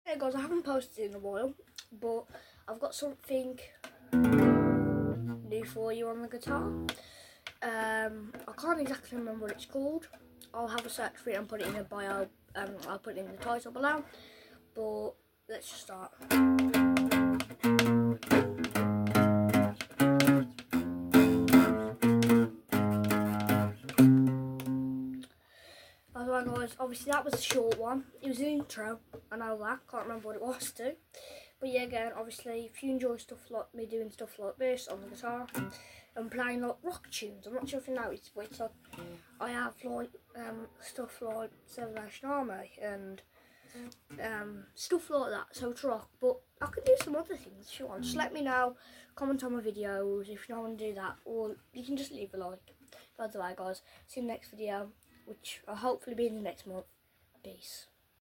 classic and quity rocky!